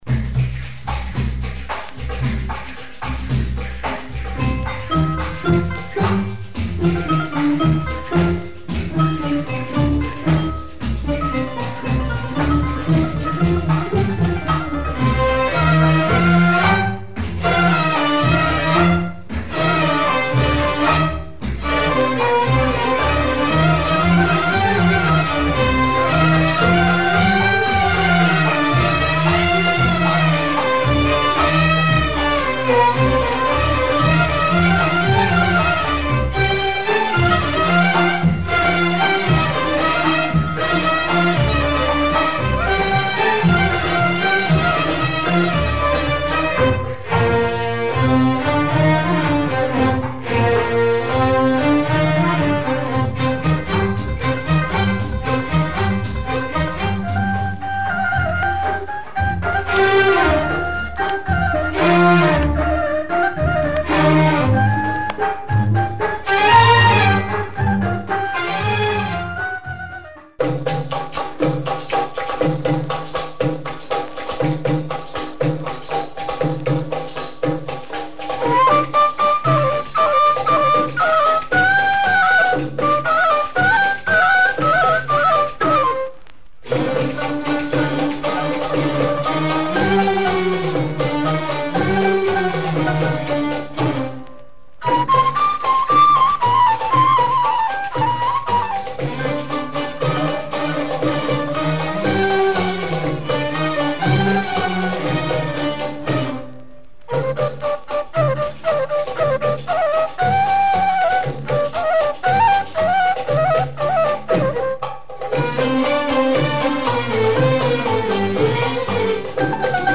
Two instrumental tracks